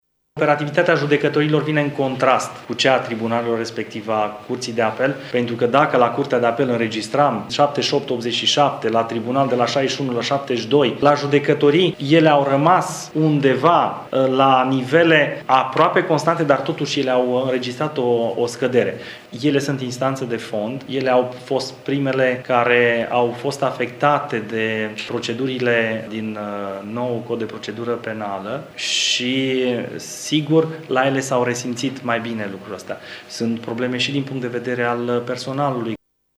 Anunţul a fost făcut cu ocazia prezentării bilanţului instanţei.